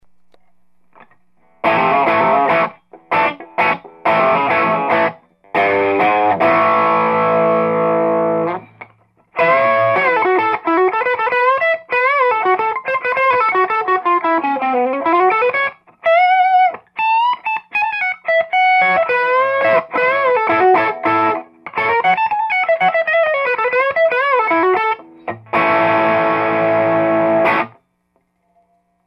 使用機材ですが、ギターはもちろんLEAD2(ハムキャンセルコイルは取り外し、ピックガードも純正品を装着、ピックアップはリアを使用) 、アンプはTwo-Rock TOPAZをクリーンセッティングで、録音はEDIROLのR-1を使用しました。